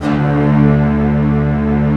Index of /90_sSampleCDs/Optical Media International - Sonic Images Library/SI1_Fast Strings/SI1_Fast octave